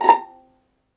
Index of /90_sSampleCDs/ILIO - Synclavier Strings/Partition F/090 0.9 MB
VIOLINP .8-L.wav